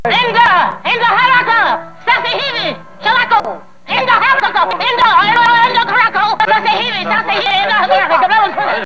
Eventually Johnson and O'Hara travel to Jill's house (at Jill's house an African  woman employee had this to say to Johnson: